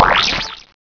snd_8889_squirt.wav